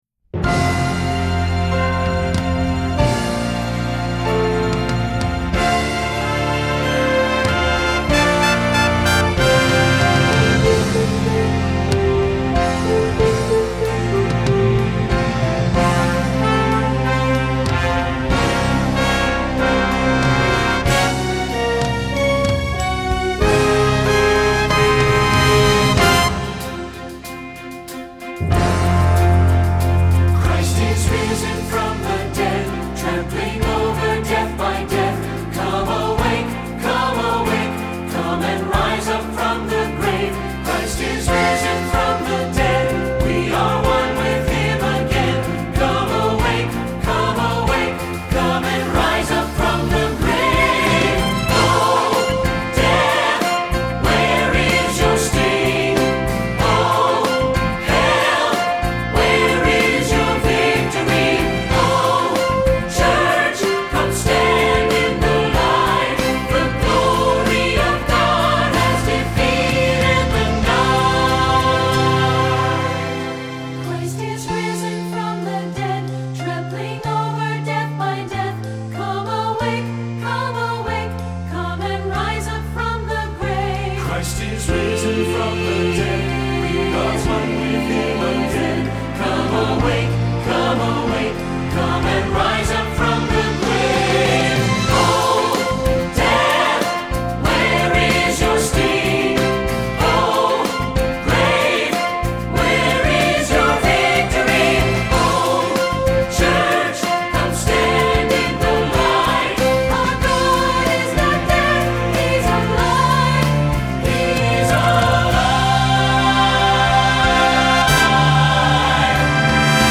Voicing: S/A/T/B - Rehearsal